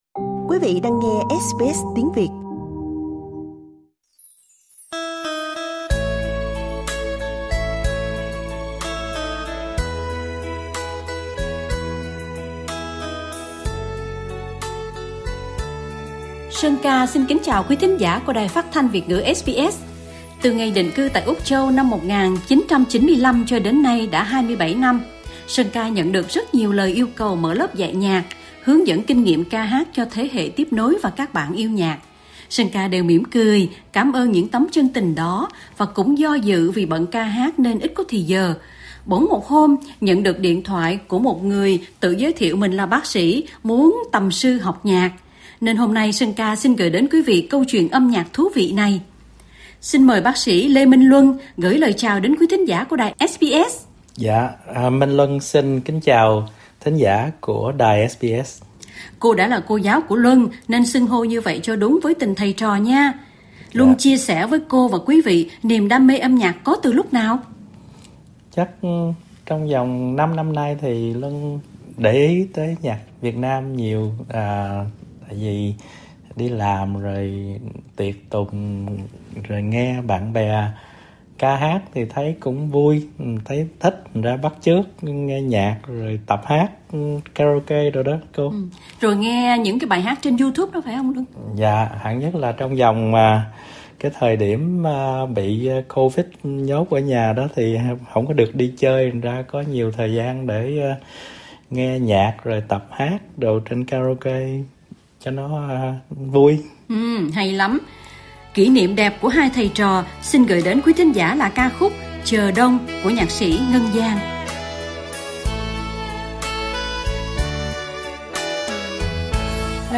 các bản song ca mời quý thính giả cùng thưởng thức.